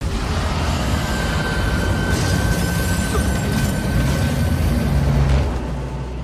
KaijuNo.8Berserkroar.mp3